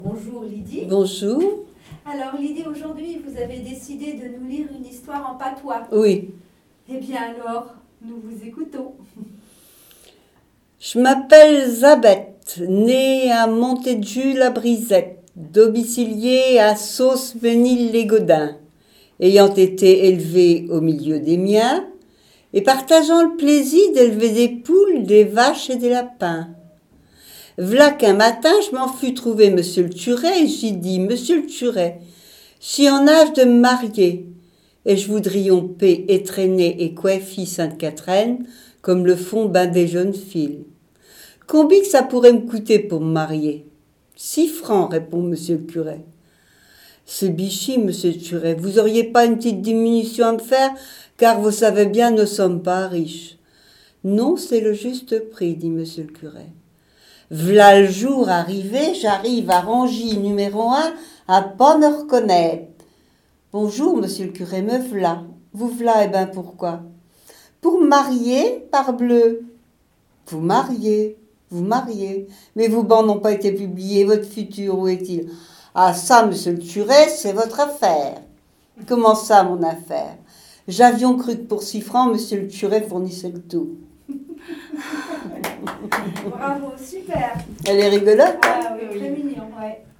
nous propose une histoire en patois !